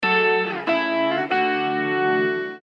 • Качество: 320, Stereo
гитара
без слов
Короткая гитарная мелодия